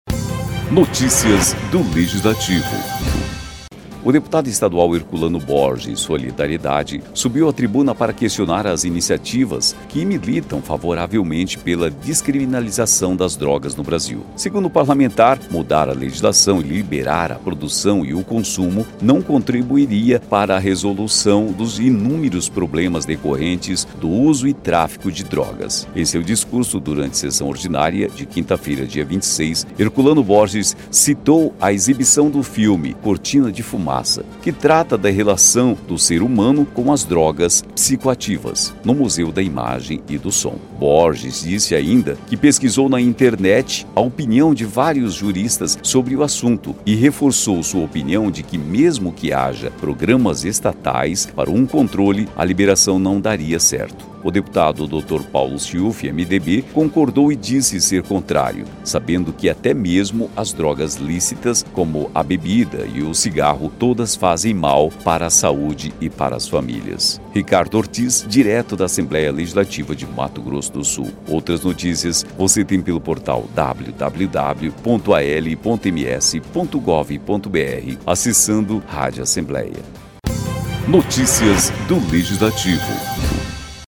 O deputado estadual Herculano Borges (SD) subiu à tribuna para questionar as iniciativas que militam favoravelmente pela descriminalização das drogas no Brasil. Segundo o parlamentar, mudar a legislação e liberar a produção e o consumo, não contribuiria para a resolução dos inúmeros problemas decorrentes do uso e tráfico de drogas.